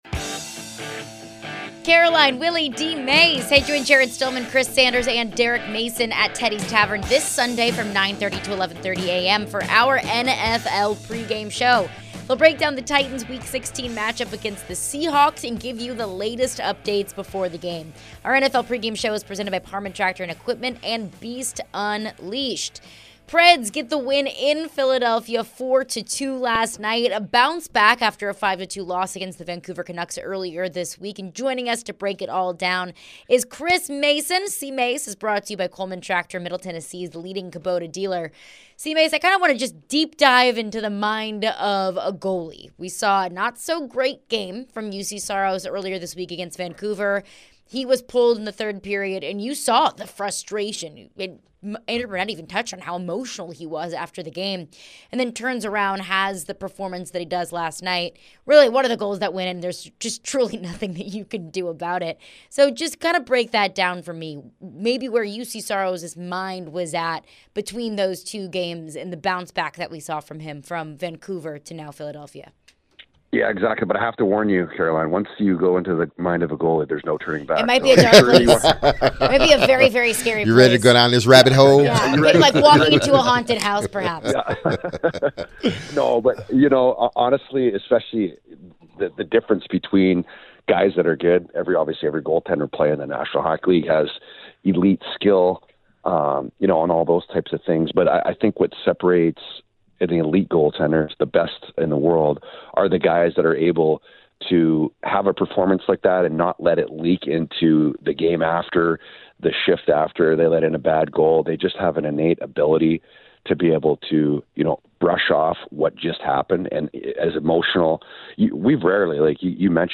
Nashville Predators former goaltender and Preds TV analyst Chris Mason joined the show and talked about the Preds win and their resilience.